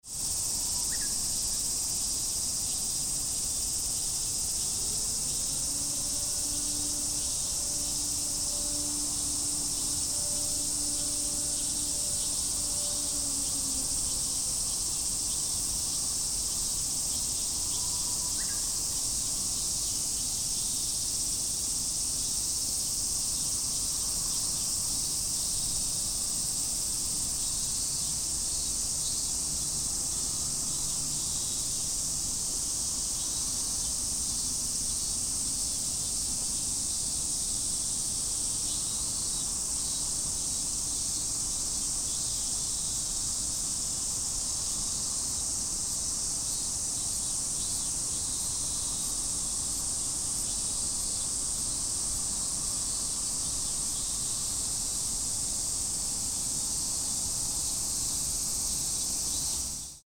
A back hill of the university where radioactive contaminations had been temporarily stored was closed , and only cicadas made noise on an exuberant hill. ♦ When I recorded this soundscape, a siren notice at 11:30 am began to resound.